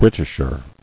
[Brit·ish·er]